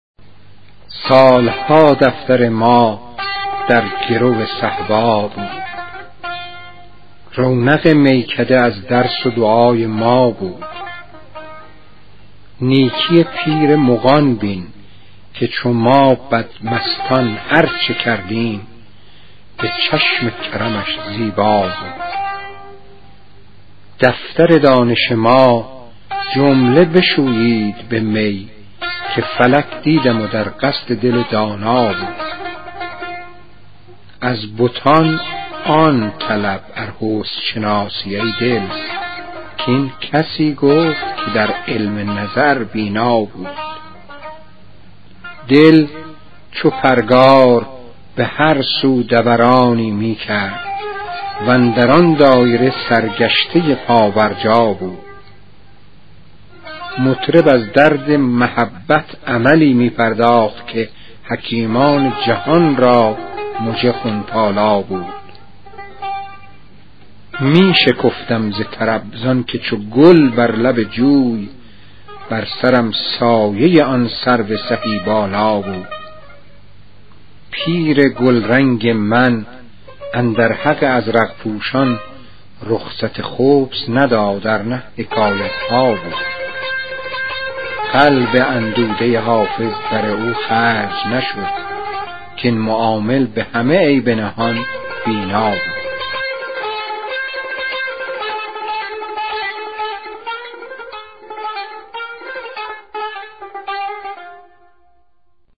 حافظ‌خوانی استاد علی موسوی گرمارودی